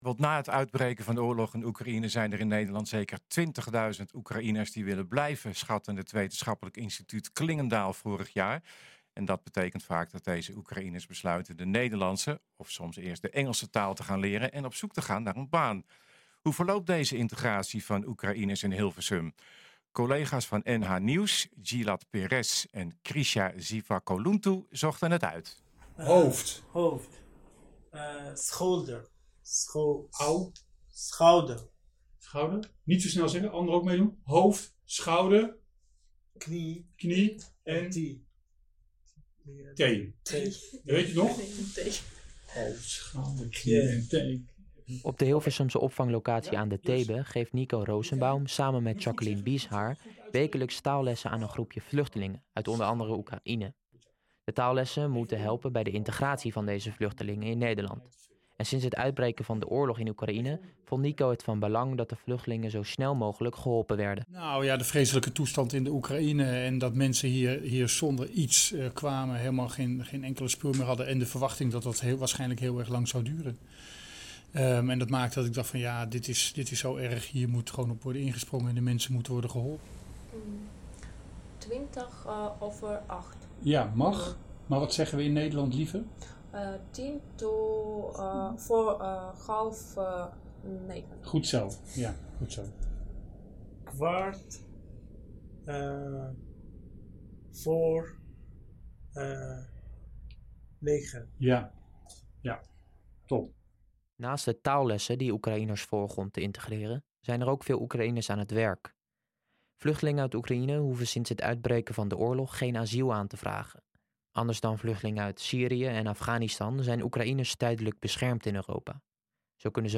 Daarnaast horen we hoe het eraan toe gaat bij een Nederlandse taalles voor vluchtelingen.
De mini-documentaire is uitgezonden in het lokale radioprogramma NH Gooi Zaterdag.